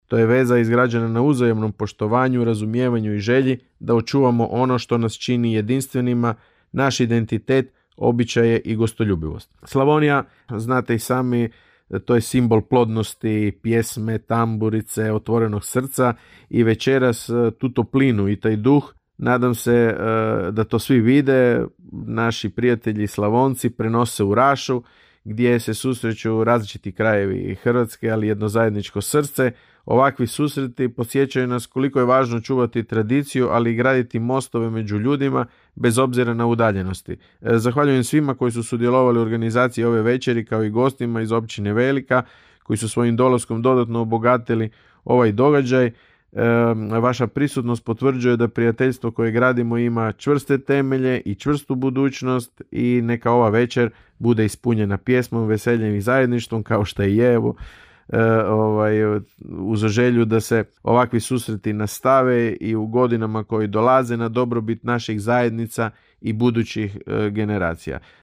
Govori raški općinski načelnik Leo Knapić: (
ton – Leo Knapić).